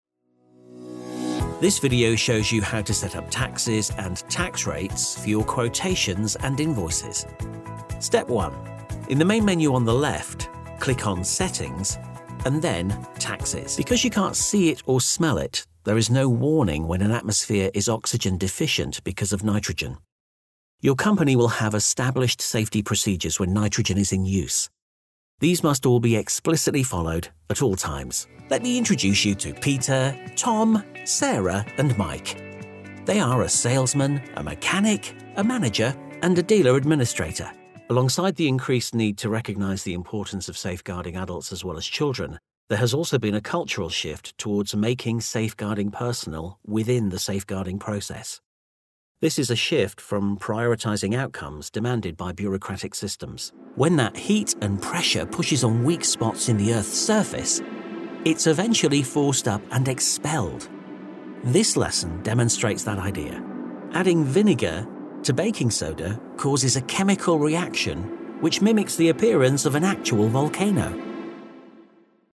British English Adult Male Voice.
Warm, friendly, conversational, ageless, engaging, authoritative, playful, authentic, genuine, smooth, knowledgeable, relaxed.
Sprechprobe: eLearning (Muttersprache):
I am a UK based, native English accent voiceover, with my own broadcast studio.
Studio: Neumann TLM193 mic / Audient id4 & Focusrite Scarlett 2i2 interfaces / Adobe Audition (CC 2020) Connections: ipDTL, ISDN, Source Connect Now, Cleanfeed.